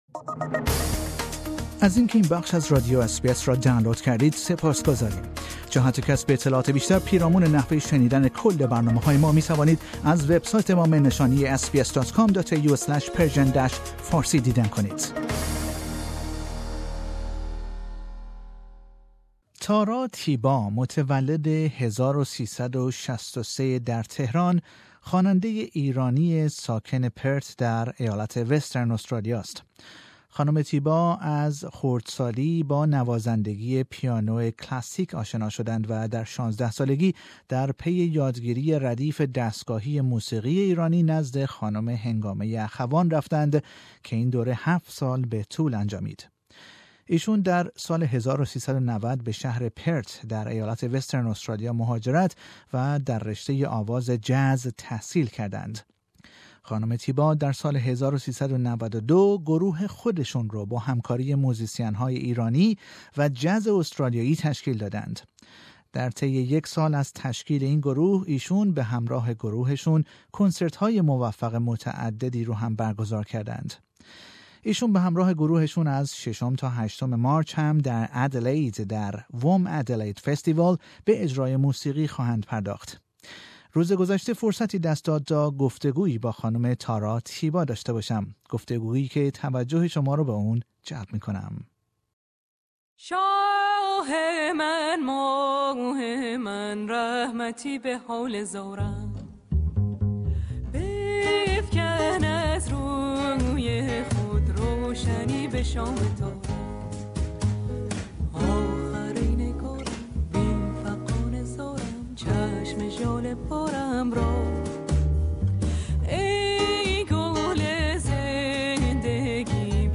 ترانه های اصیل و ماندگار ایرانی با رایحه موسیقی جاز